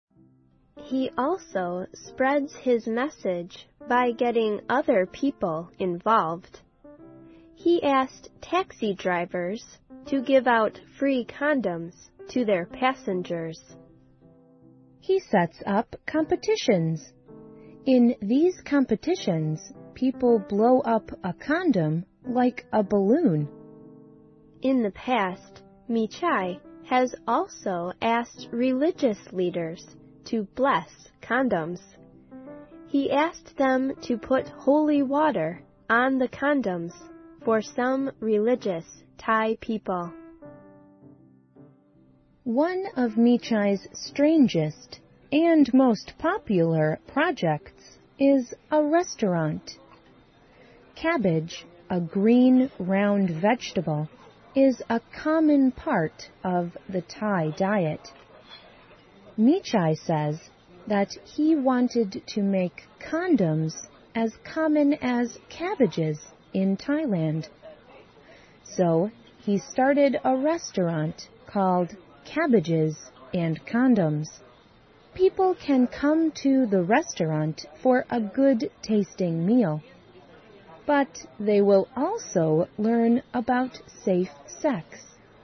环球慢速英语 第119期:泰国对抗艾滋病的不寻常方法(6)